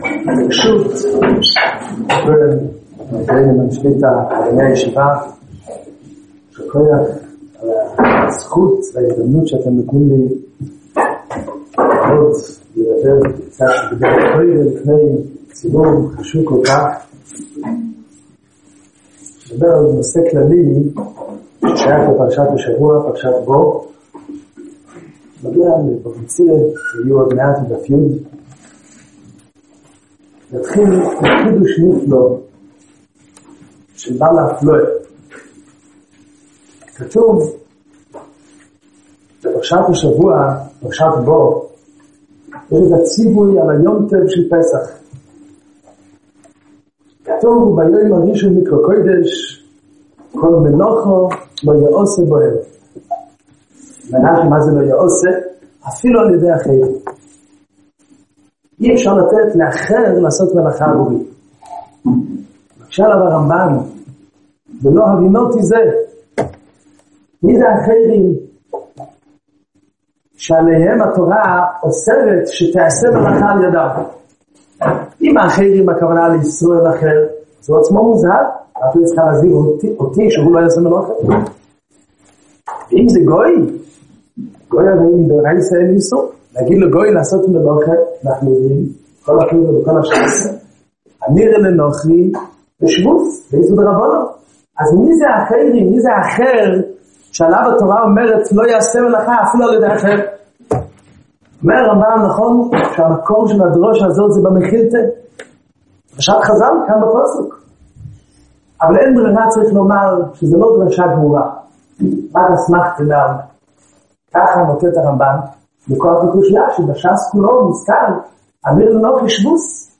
פרשת בא תשע”ו – אמירה לעכו”ם קודם מתן תורה [שיעור בישיבת טלזסטון]